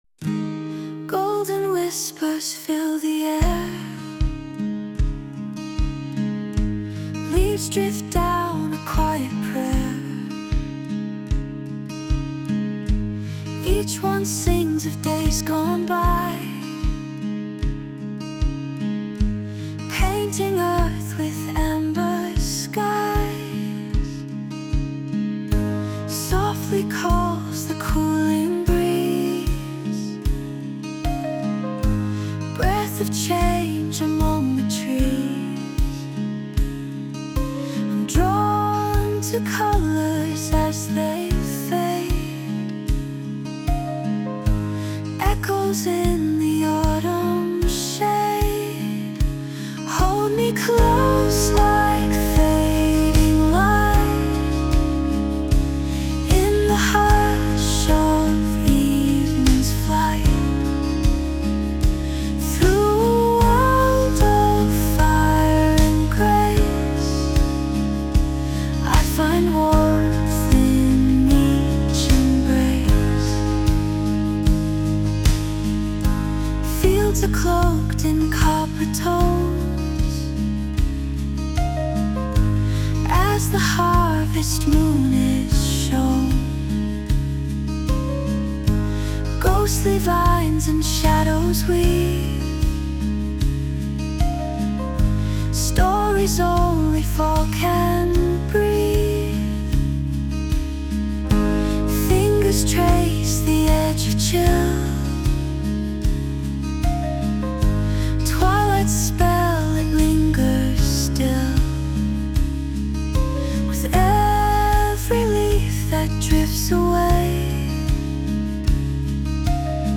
洋楽女性ボーカル著作権フリーBGM ボーカル
著作権フリーオリジナルBGMです。
女性ボーカル（洋楽・英語）曲です。
秋をイメージした優しいバラード曲に仕上げました。